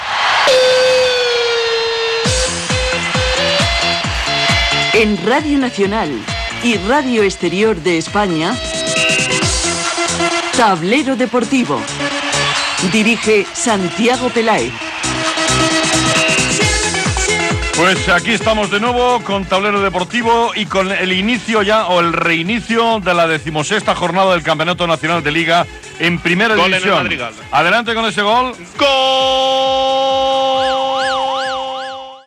Identificació del programa i gol al camp del Madrigal.
Esportiu
FM